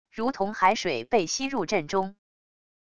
如同海水被吸入阵中wav音频